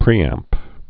(prēămp)